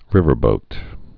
(rĭvər-bōt)